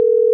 Agents will hear only hear a single beep as the call is connected.
predictive_beep.wav